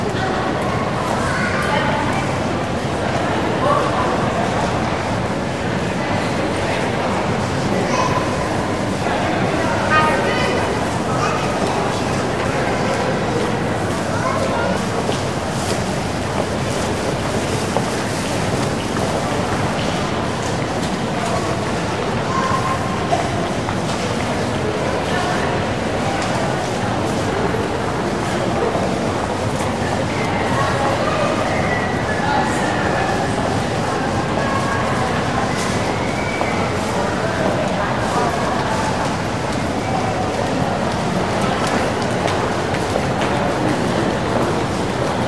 Indoor atmospheres 2